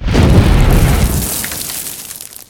coverblow.ogg